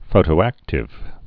(fōtō-ăktĭv)